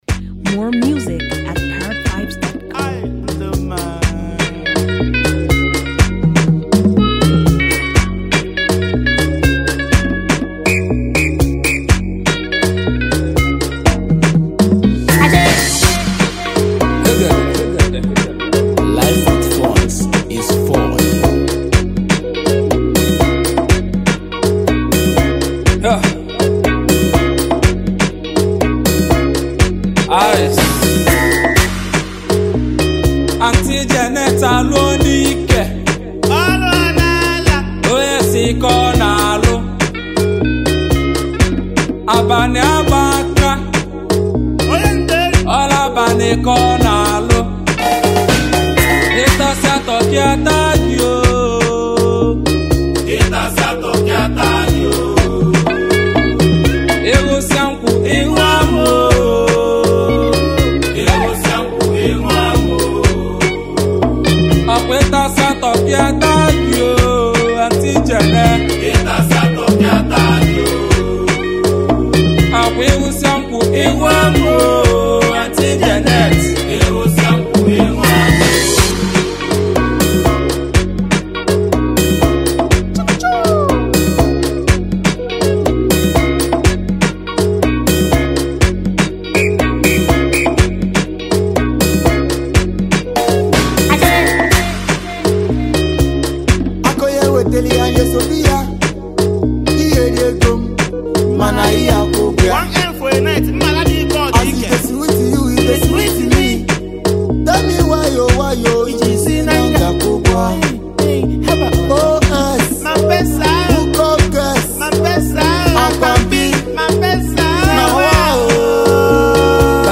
Highly gifted Nigerian rapper and songwriter